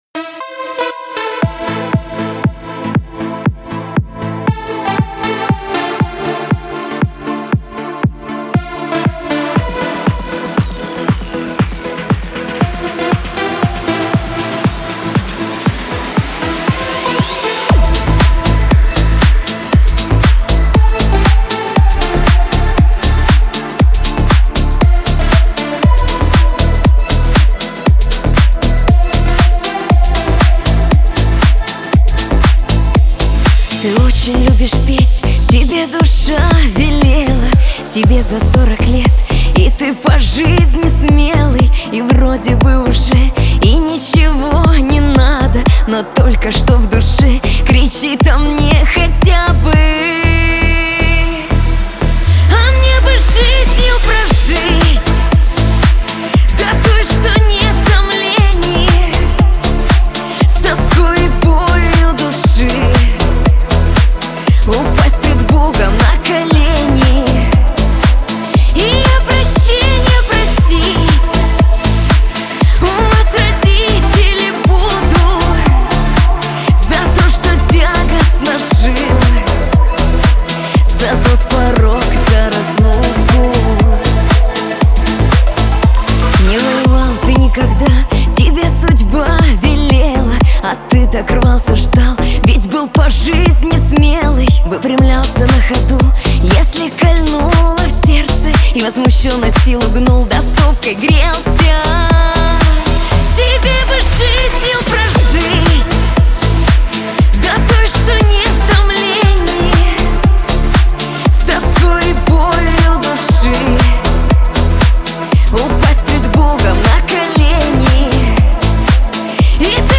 эстрада
Шансон
грусть
диско